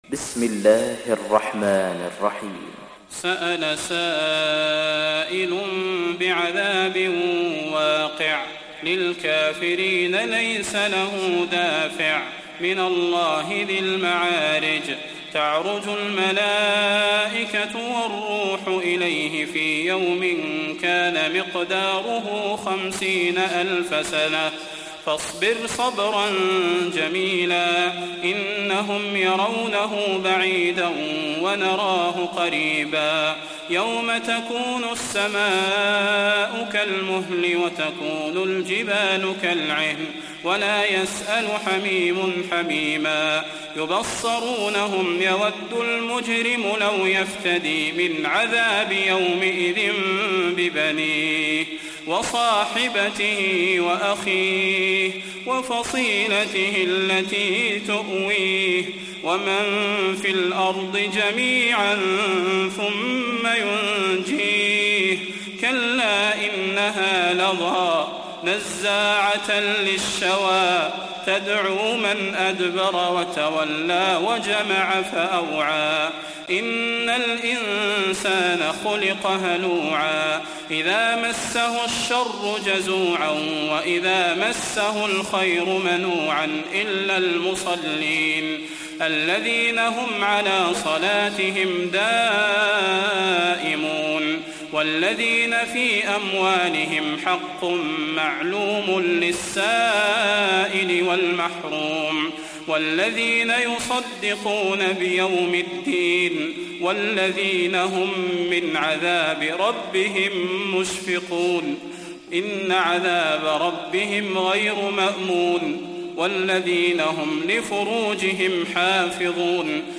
تحميل سورة المعارج mp3 بصوت صلاح البدير برواية حفص عن عاصم, تحميل استماع القرآن الكريم على الجوال mp3 كاملا بروابط مباشرة وسريعة